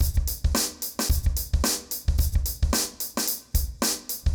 RemixedDrums_110BPM_33.wav